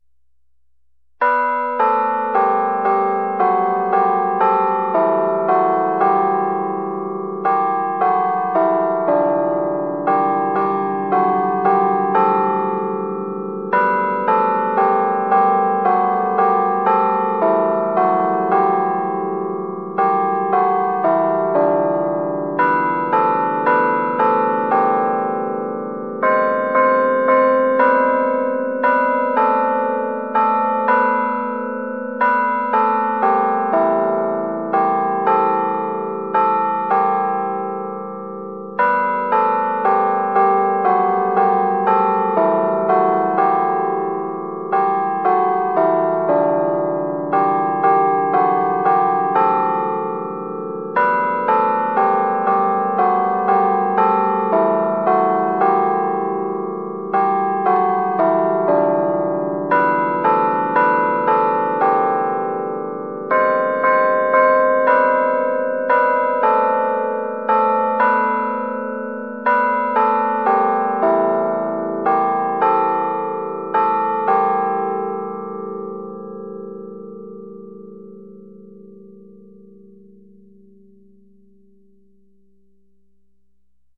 Our carillon controllers use real bell melodies.
• Realistic digital bell sounds that replicate traditional carillons